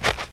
snow-01.ogg